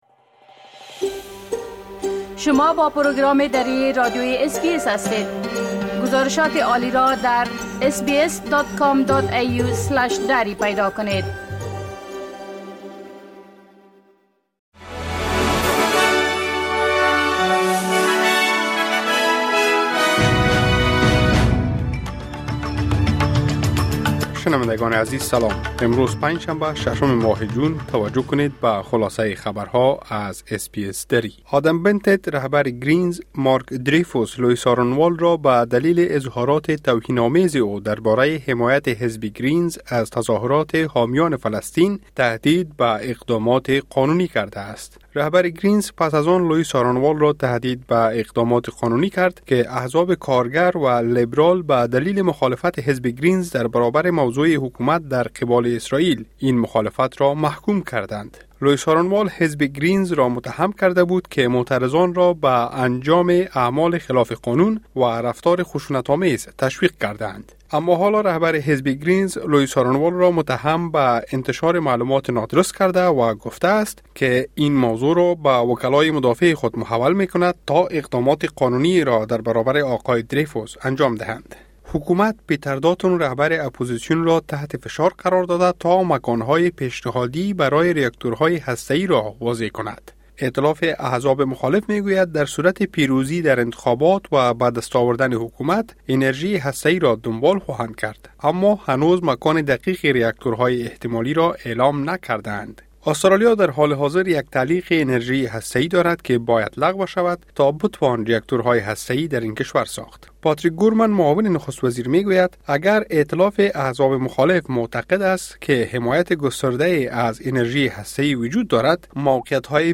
خلاصۀ مهمترين خبرهای روز از بخش درى راديوى اس بى اس|۶ جون ۲۰۲۴